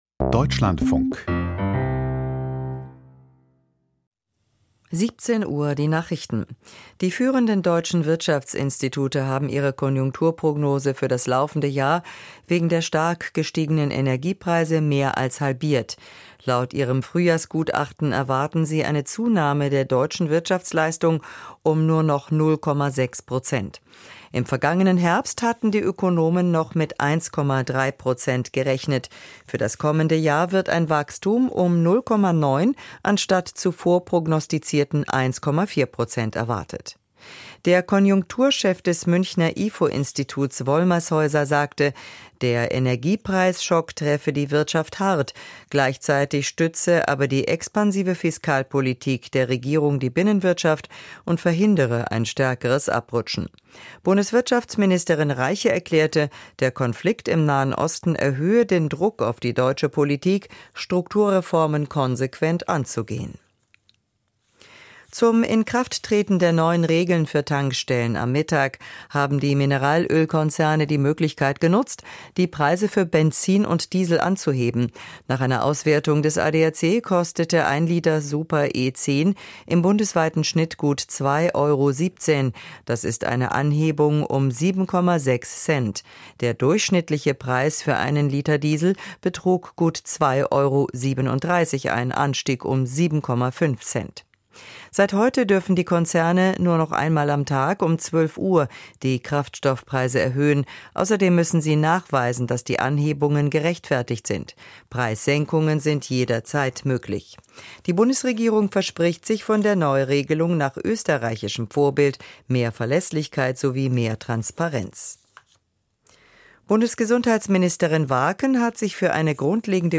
Die Nachrichten vom 01.04.2026, 17:00 Uhr